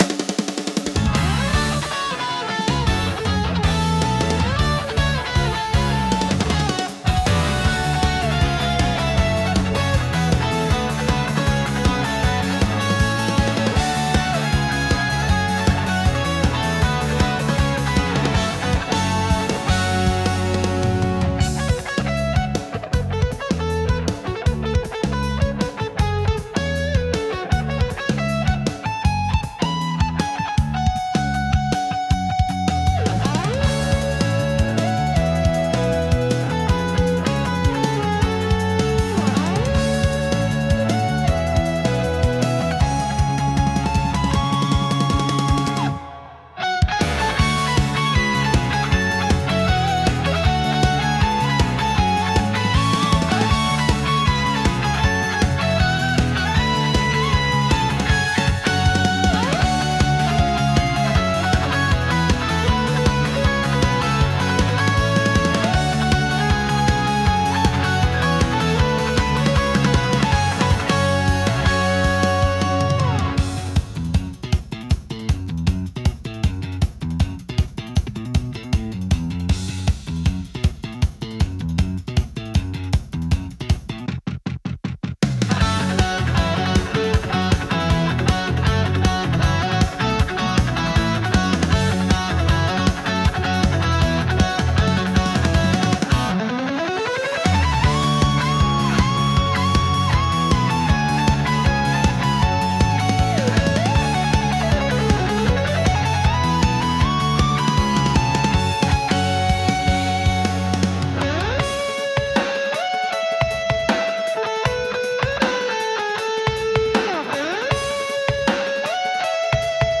サラリーマンが商談先に突撃しにいくBGMです（設定が滅茶苦茶だ）